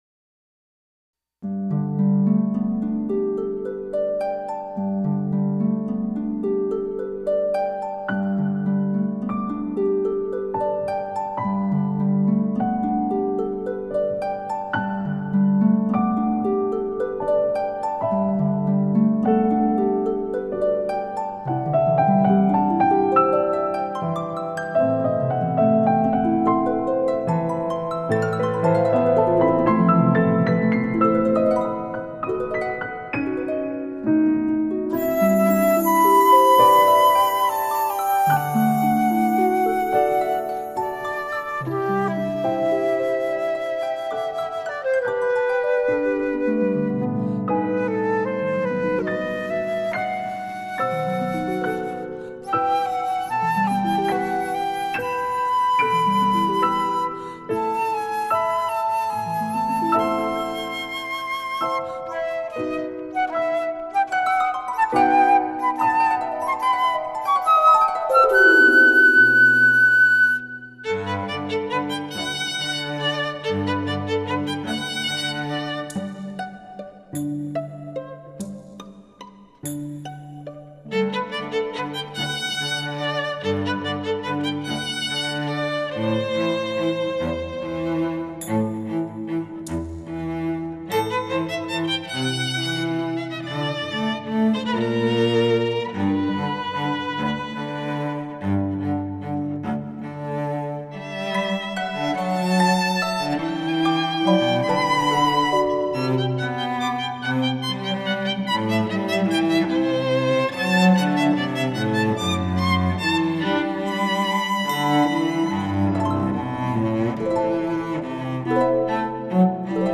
浪漫新世纪音乐
钢琴
缱绻的琴声缭绕，层层裹覆我对你无尽的缠绵；大提琴呢喃低语，倾诉著我们醇厚徐缓的爱意。
长笛与小提琴往来纠缠的情挑勾引，我们炽热的情感于是无法克制的绵延……